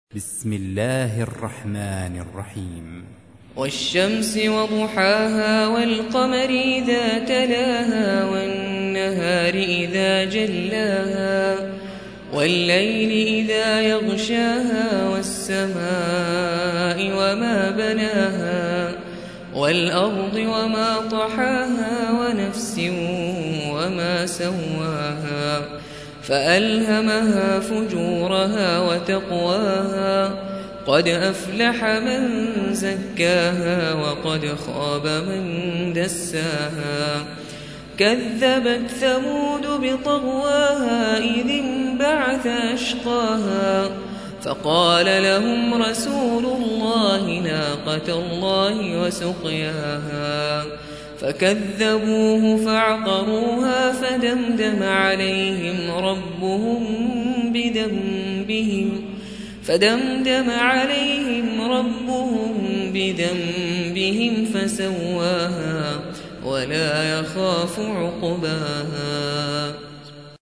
91. سورة الشمس / القارئ